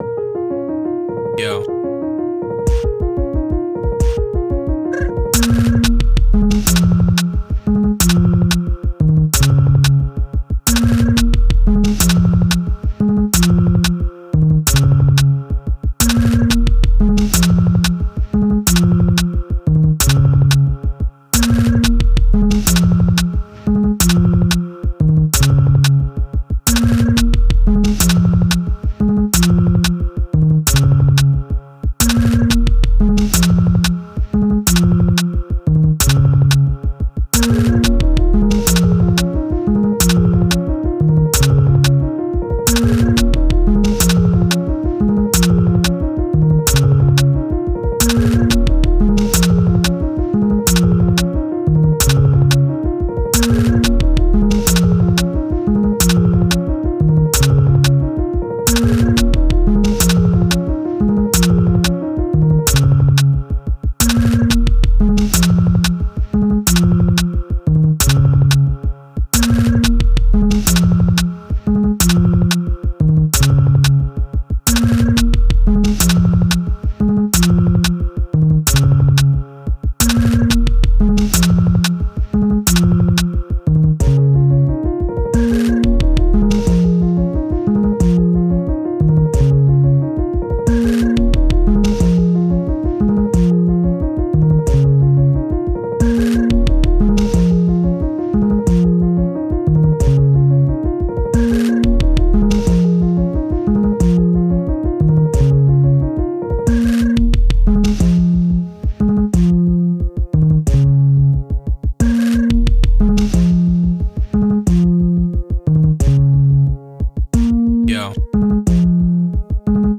Жанр: Trap